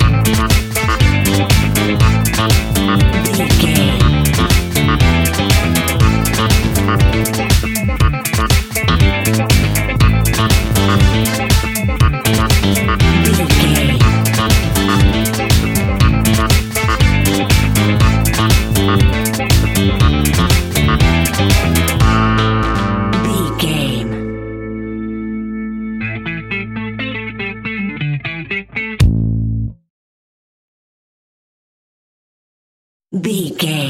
Aeolian/Minor
funky
groovy
uplifting
driving
energetic
bass guitar
electric guitar
drums
synthesiser
electric organ
brass
funky house
disco house
electro funk
upbeat
synth leads
Synth Pads
synth bass
drum machines